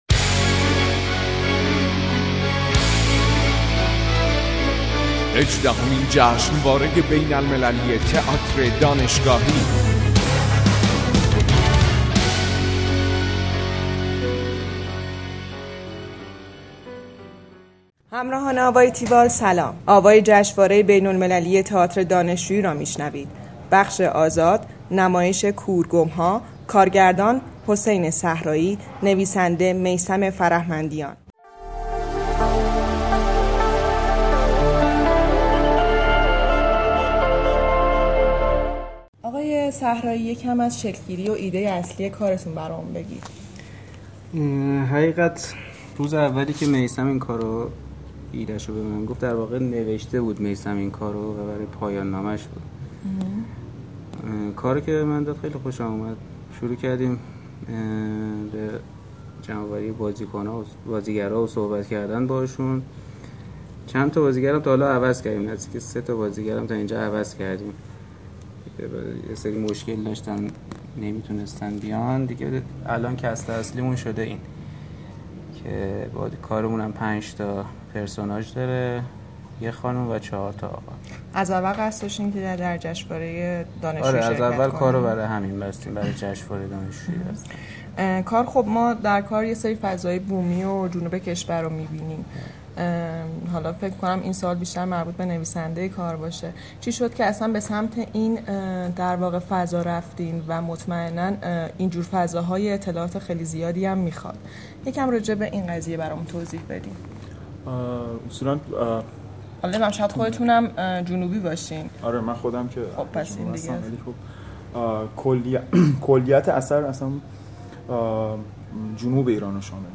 گفتگوی تیوال با